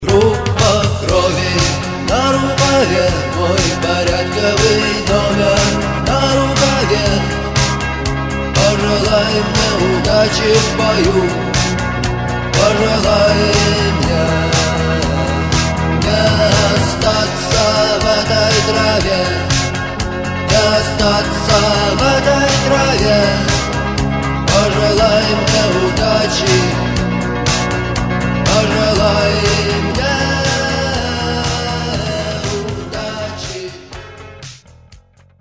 Рок рингтоны
Пост-панк , New wave